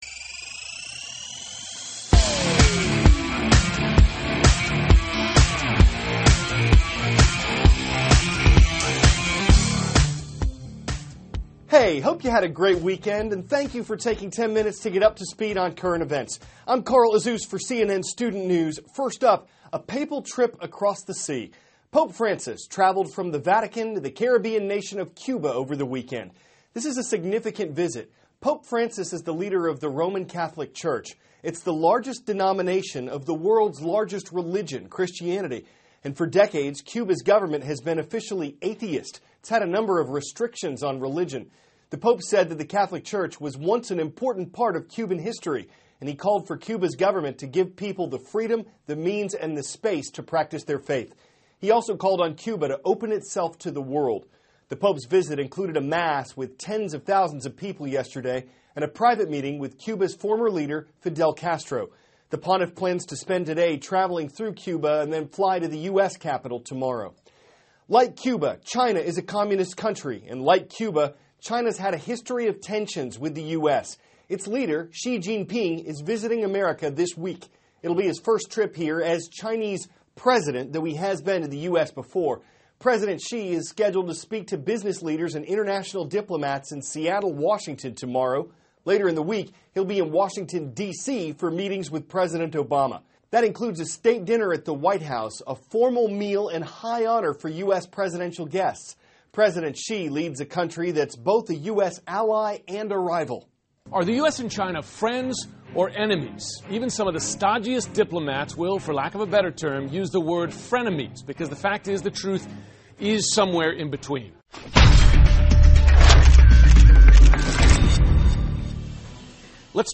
*** CARL AZUZ, cnn STUDENT NEWS ANCHOR: Hey.